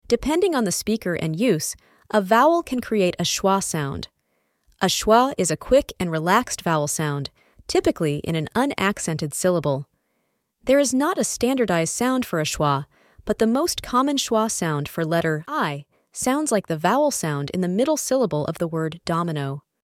I-itch-more-information-AI-2.mp3